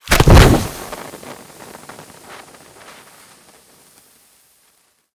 Incendiary_Near_01.ogg